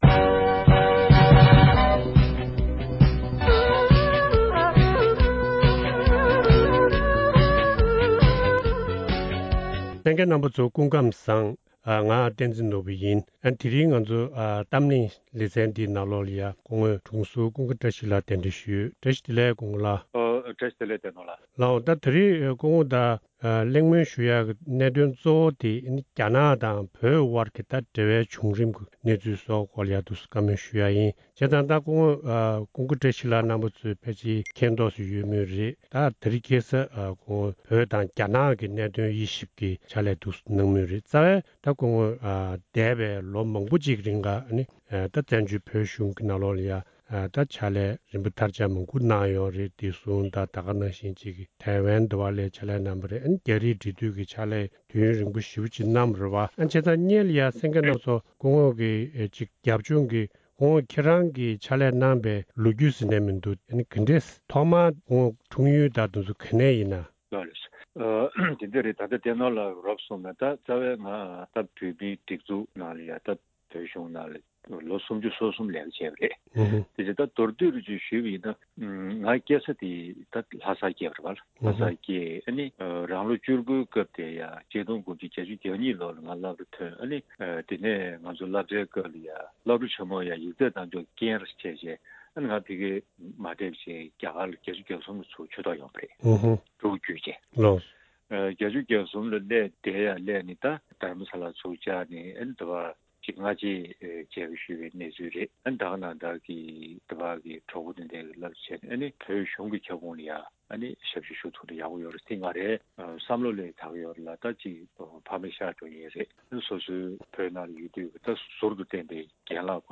བོད་དང་རྒྱ་ནག་གི་འབྲེལ་བའི་གནད་དོན་ཐད་གླེང་མོལ།